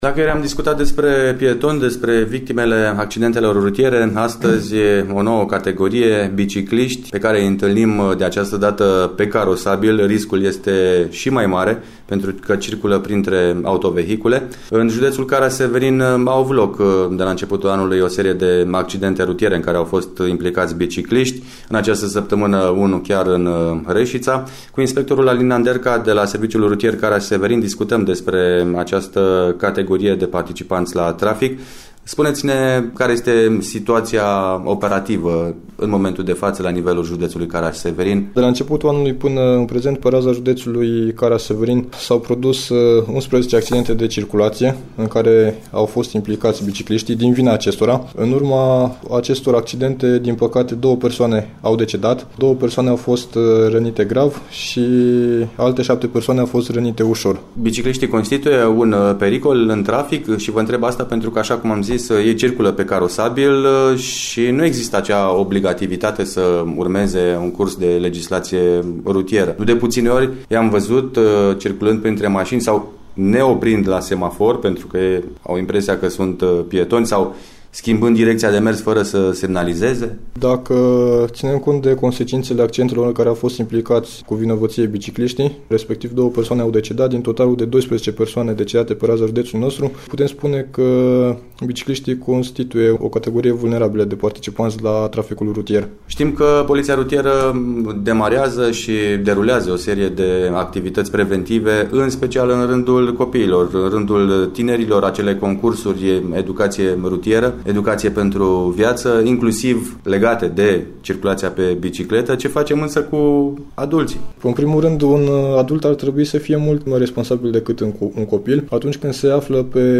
Accidentările cu bicicleta a minorilor se datorează de cele mai multe ori erorilor făcute de ei. Din acest motiv este important ca părinţii să îi înveţe cum să evite accidentele, spune inspectorul principal